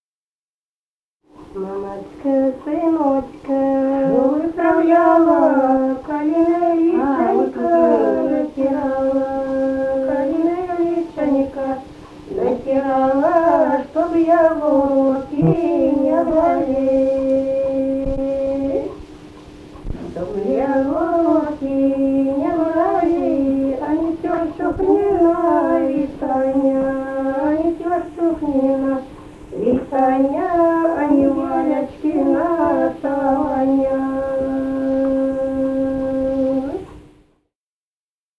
| diskname = Музыкальный фольклор Климовского района
«Мамочка сыночка выправляла» (свадебная).